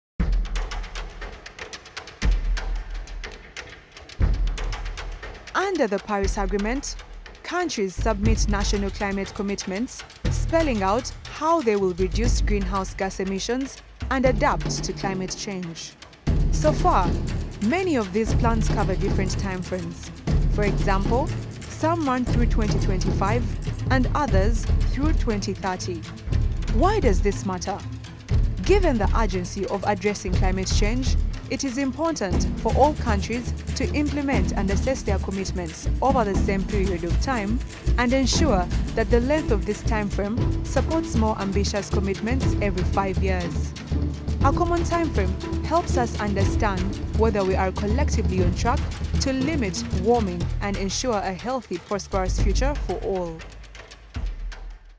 English (African)
Promos
PreSonus AudioBox 96 Studio Bundle
Young Adult
Middle-Aged